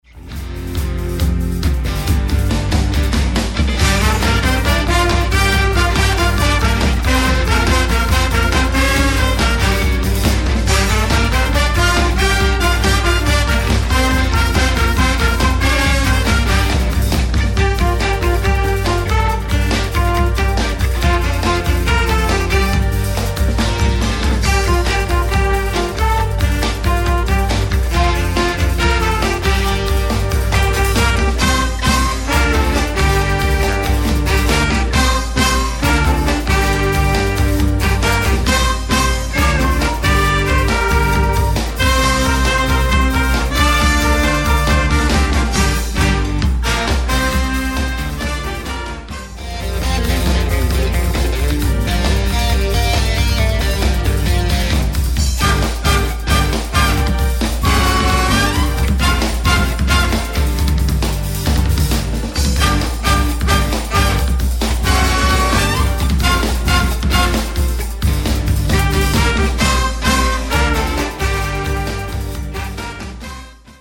Street Band